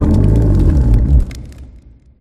Grito